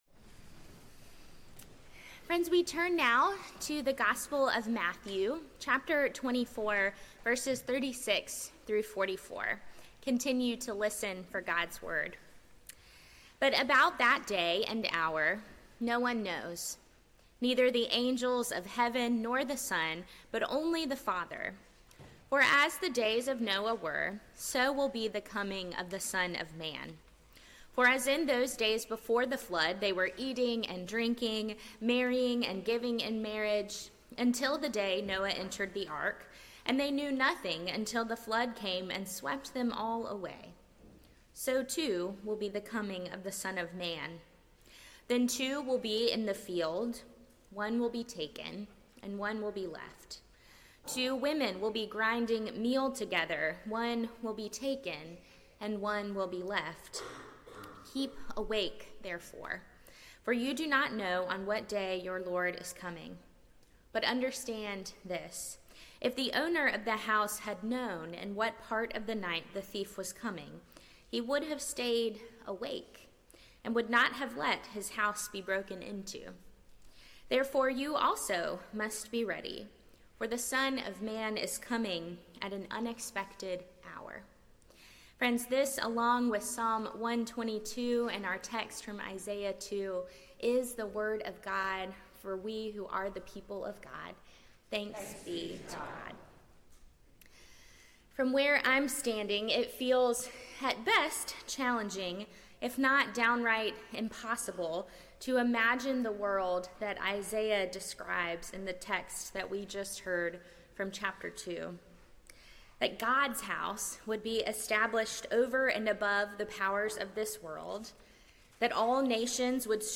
Sermon
First Sunday of Advent